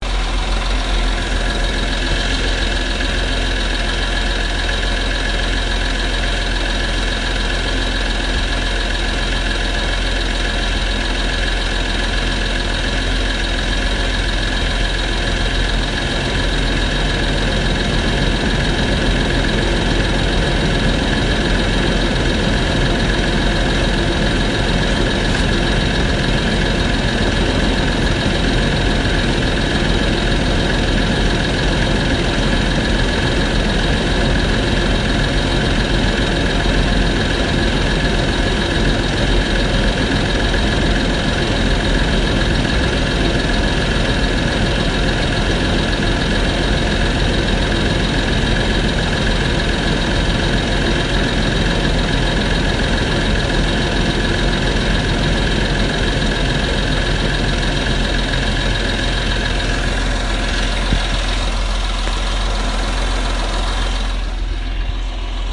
奔驰 " 梅赛德斯奔驰内部行驶速度正常
描述：这个录音我做了一部短片，它是汽车和车轮附近的几个麦克风。我使用dpa dpa lavalier mkh 406 schoeps cmc 5和mkh 60。
标签： 我rcedes 汽车 电机 驱动器 汽车 发动机
声道立体声